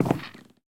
creaking_heart_step5.ogg